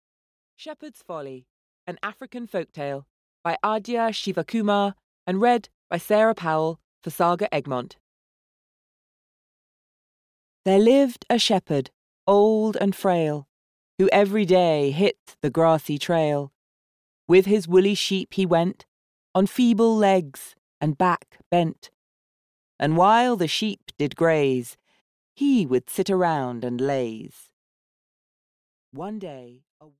Audio knihaShepherd's Folly. An African Folk Tale (EN)
Ukázka z knihy